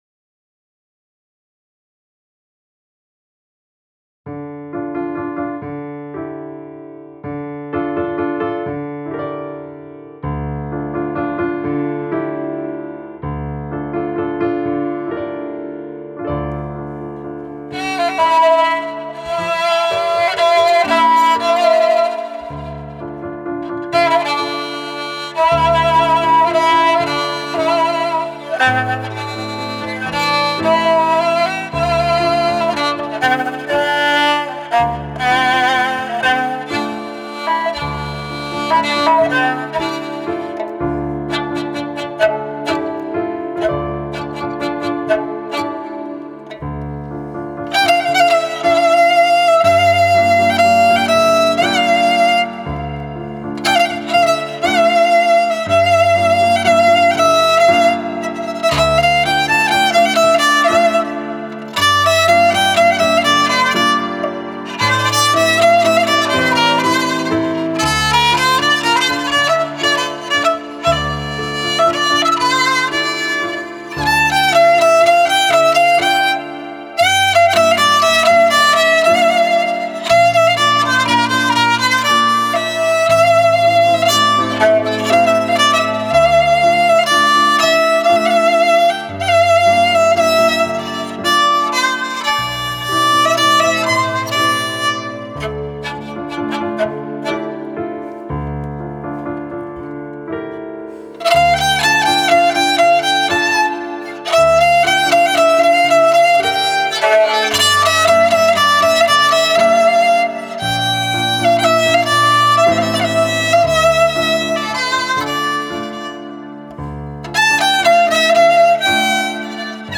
قطعه‌ای بی‌کلام است
با فضایی احساسی و روایتی موسیقایی از درد و سکوت دریاچه‌ای
کمانچه